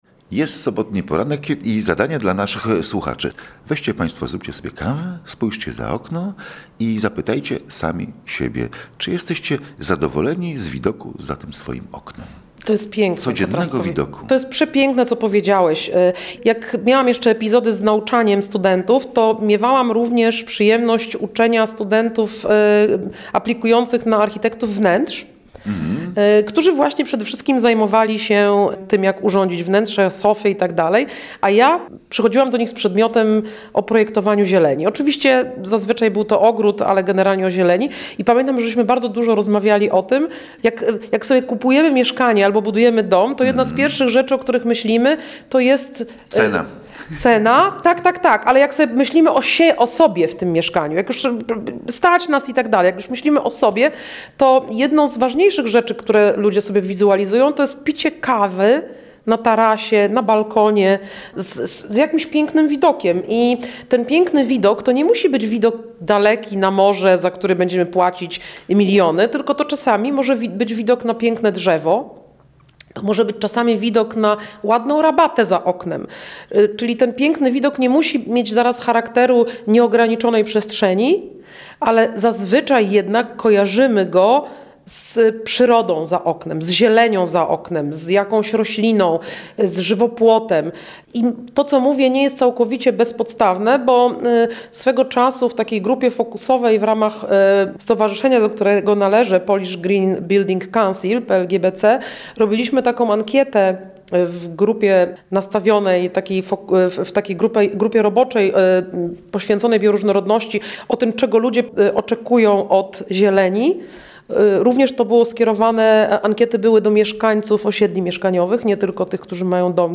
Taka jest geneza rozmowy z architektką krajobrazu